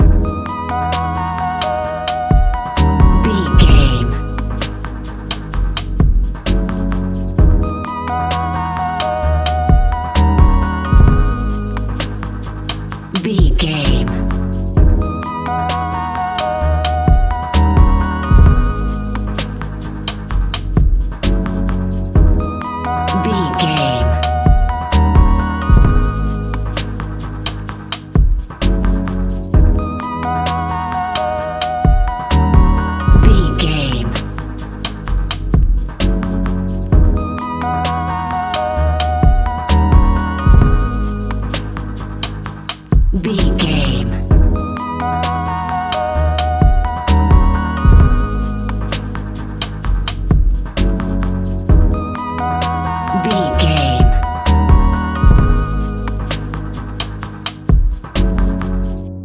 Ionian/Major
C♯
laid back
Lounge
sparse
new age
chilled electronica
ambient
atmospheric
instrumentals